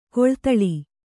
♪ koḷtaḷi